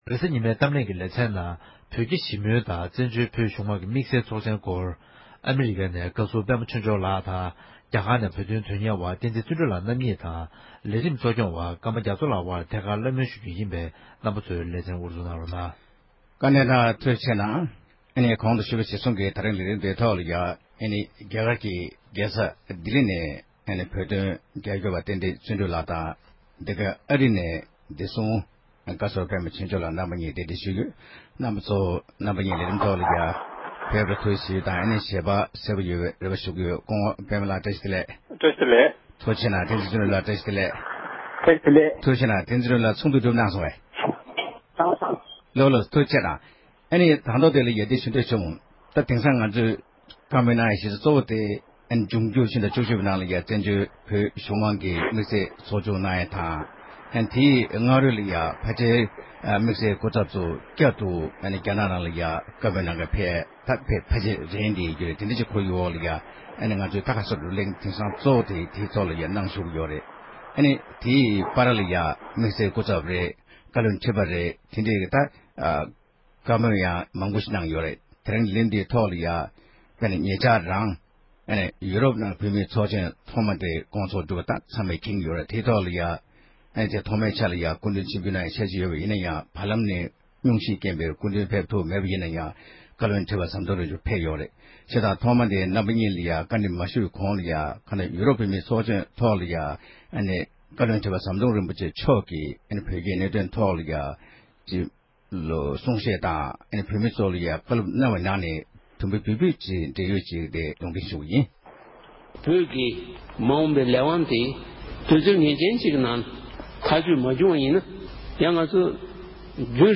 གཏམ་གླེང